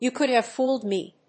アクセントYou còuld have fóoled me!